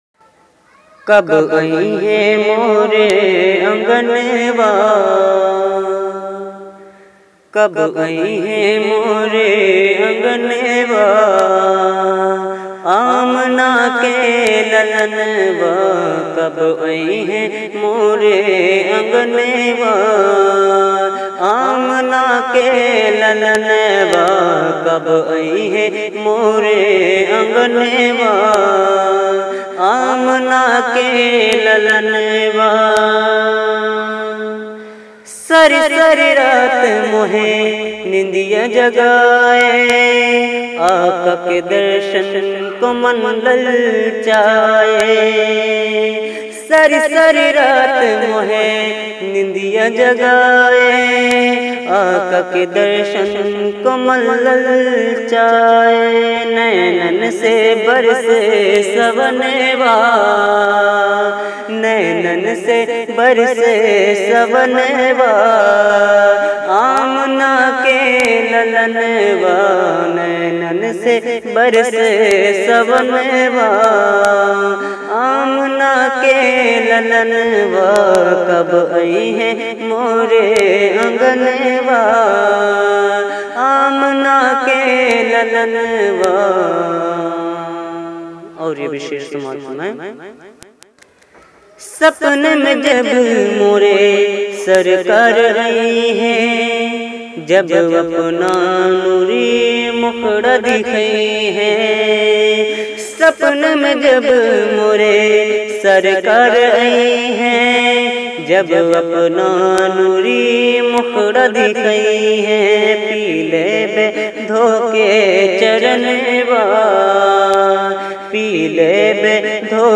Bhojpuri New Naat